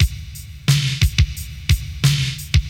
• 89 Bpm Hip Hop Drum Loop Sample D# Key.wav
Free breakbeat - kick tuned to the D# note. Loudest frequency: 1227Hz
89-bpm-hip-hop-drum-loop-sample-d-sharp-key-Hiq.wav